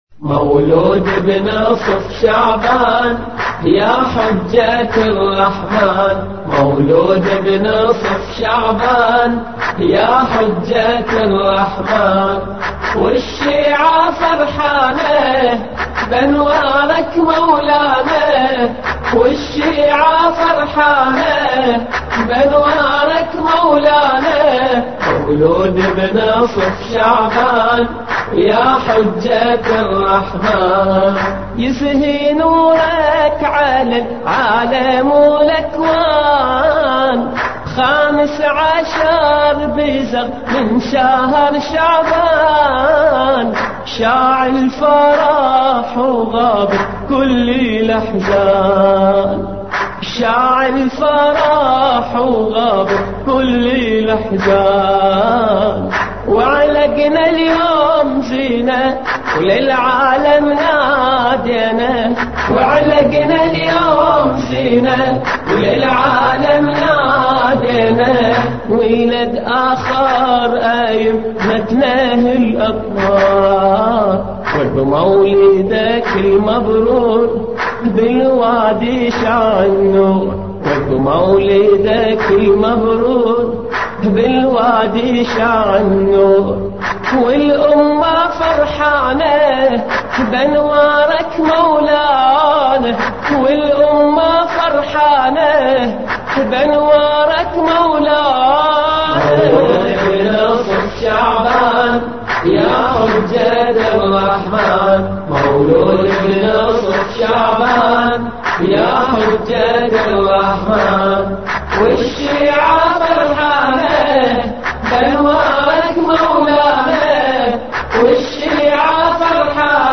مولودی نیمه شعبان عربی